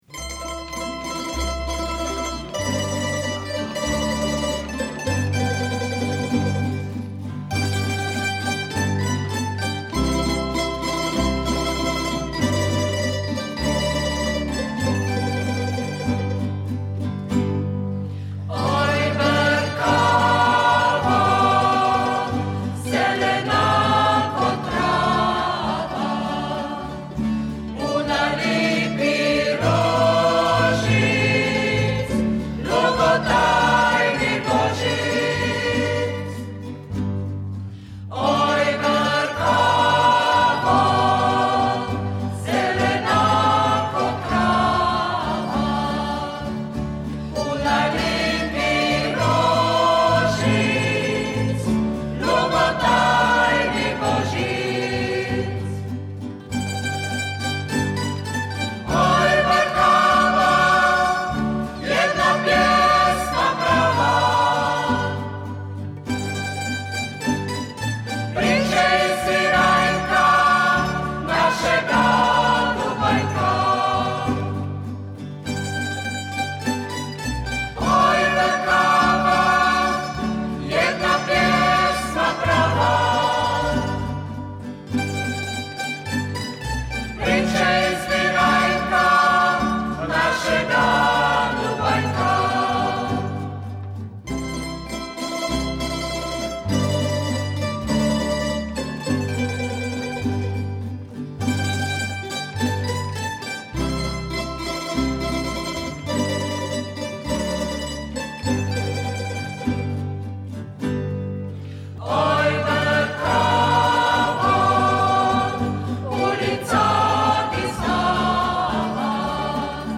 Zbor i tamburica Frakanava